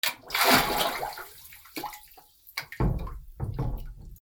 水に落ちる 洗面器を沈める
『ジャボン』